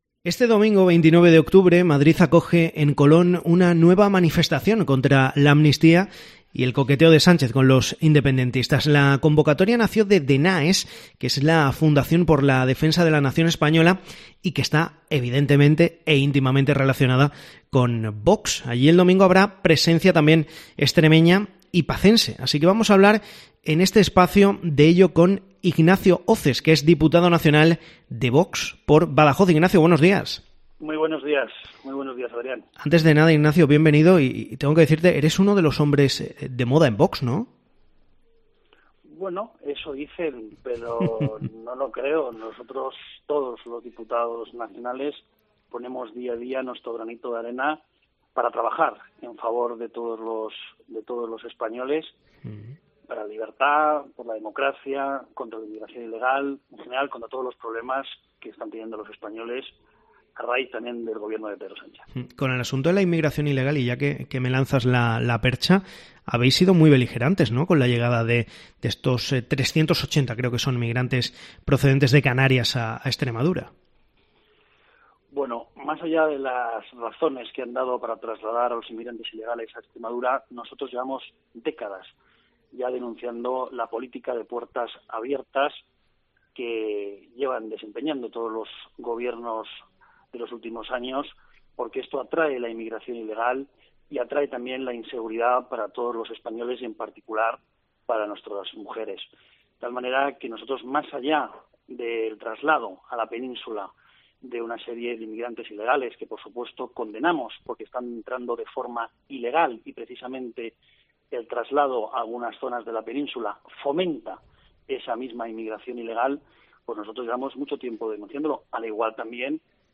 Uno de los hombres de moda dentro de Vox, el diputado por Badajoz, Ignacio Hoces, ha pasado por COPE Extremadura, donde ha defendido la manifestación del 29-O, convocada en Madrid por DENAES para protestar contra la amnistía y las negociaciones de Sánchez con los independentistas: "Colón será un clamor".